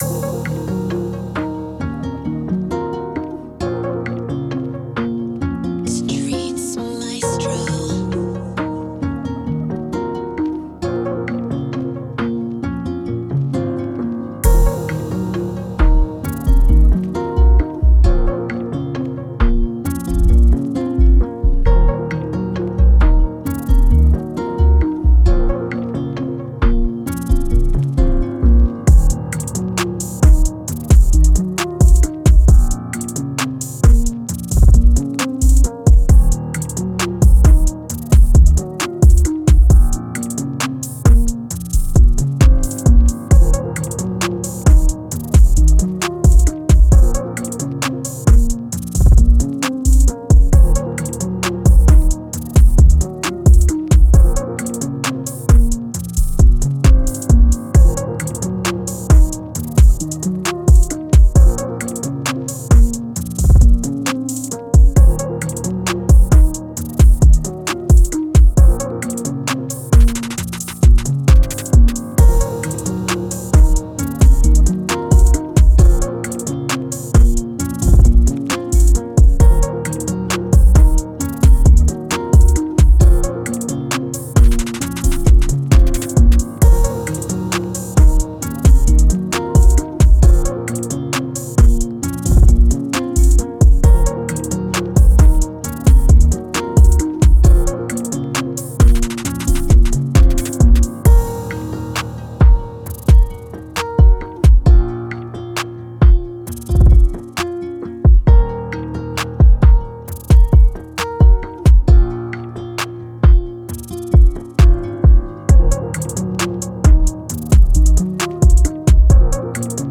Mellow Guitar Type Beat
Moods: mellow, dramatic, laid back
Genre: Rap
Tempo: 133